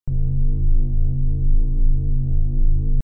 ship_cushion.wav